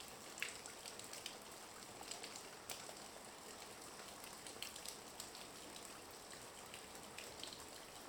rain_outside.ogg